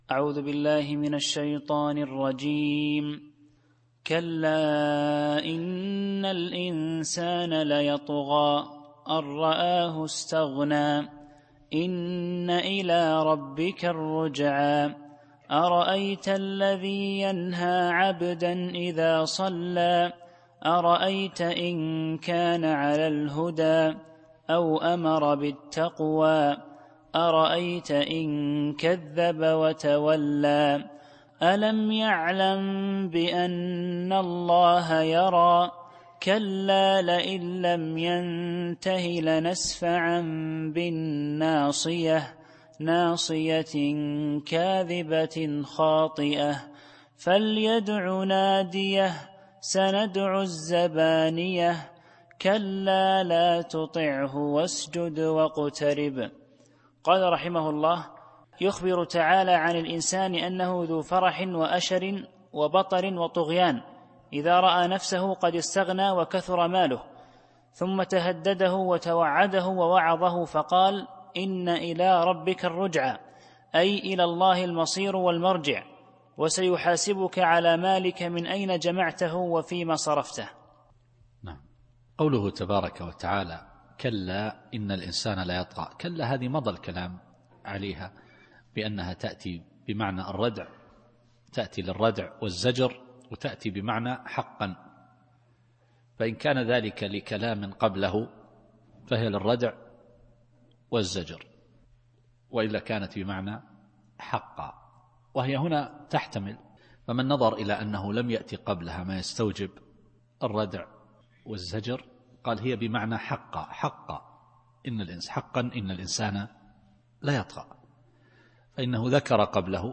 التفسير الصوتي [العلق / 8]